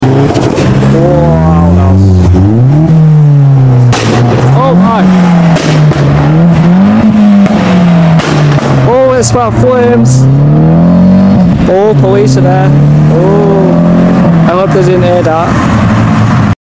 Corsa VXR Insane Pops And Sound Effects Free Download